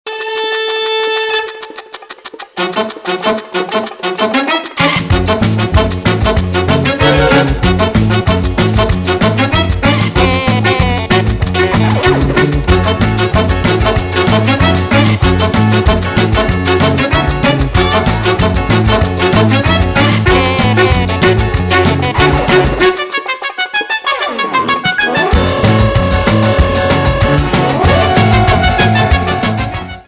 танцевальные
позитивные , прикольные , веселые
барабаны , труба , без слов , инструментальные